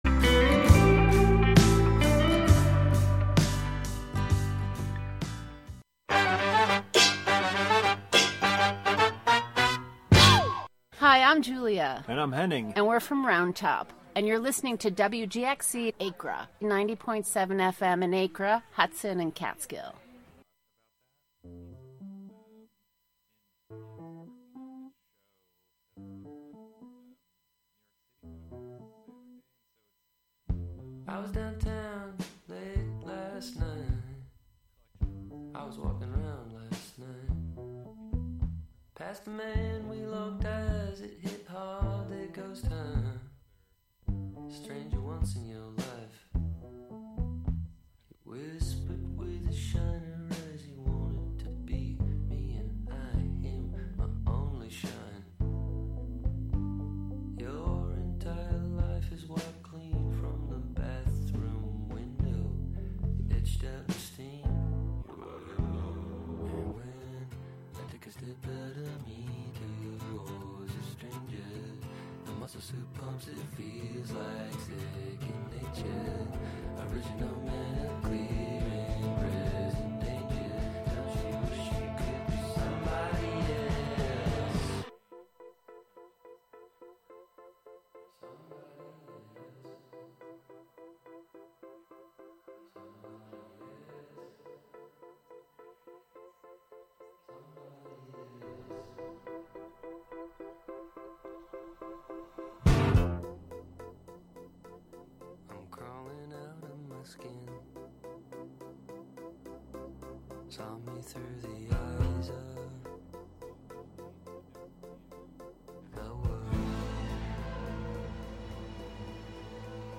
Each broadcast features candid conversations with both professional and aspiring artists, uncovering the heart of their creative process, the spark of their inspiration, and the journey that brought them into the art scene. From painters and sculptors to musicians and writers, we celebrate the richness of artistic expression in our community and beyond.